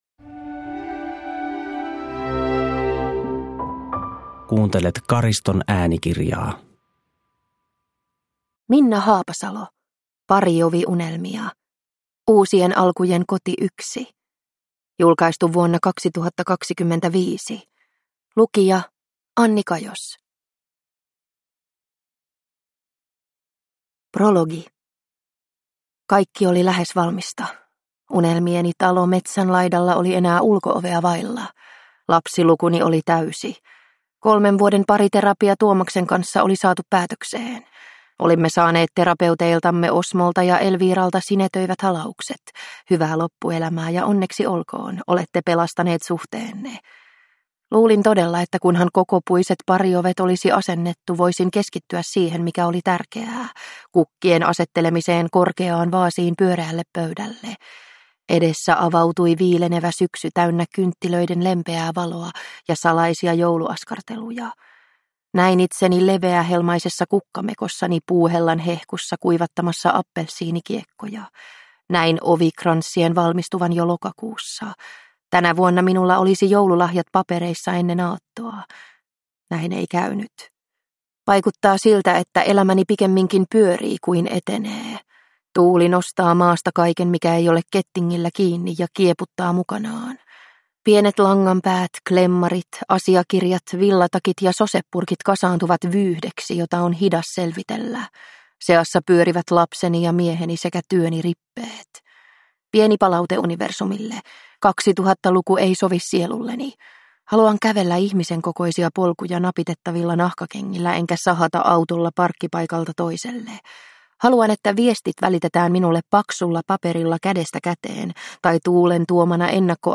Parioviunelmia – Ljudbok